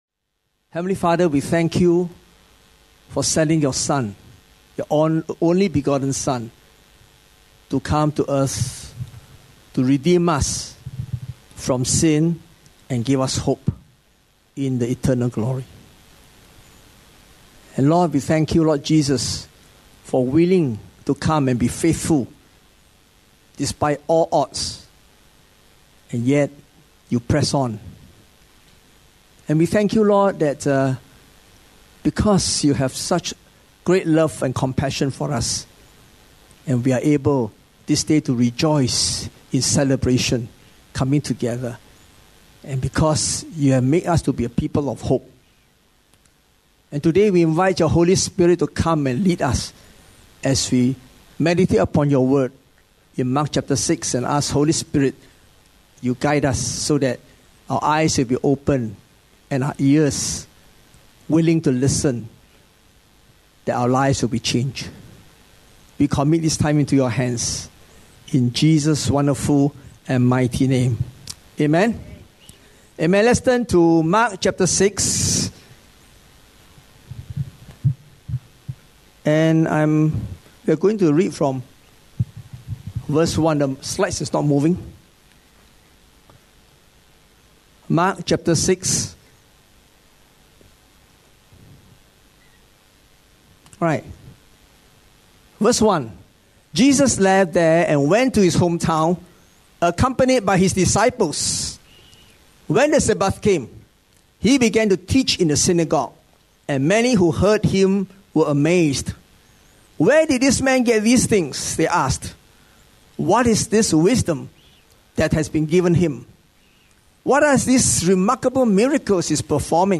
Otherwise just click, the video / audio sermon will just stream right to you without download.